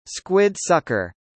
・吸盤「squid sucker」スクウィッド　サッカー